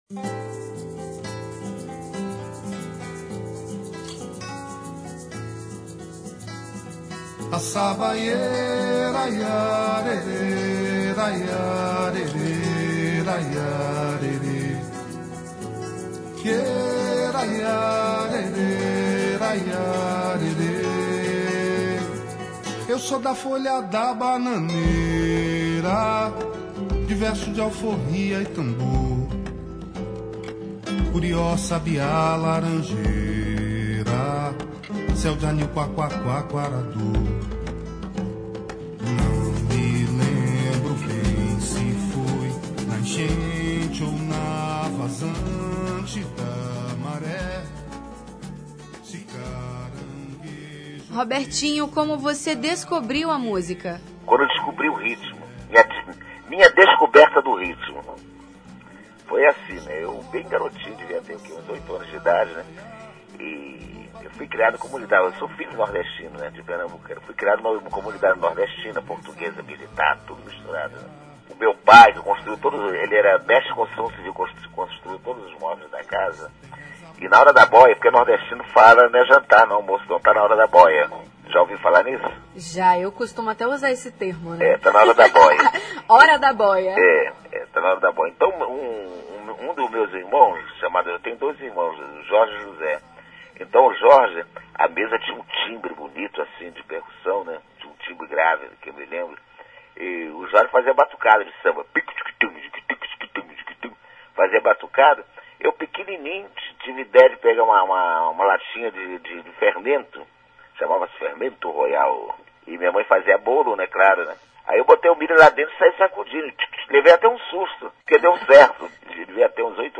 Confira a entrevista dos músicos. Padedês de Sararás Download : Padedês de Sararás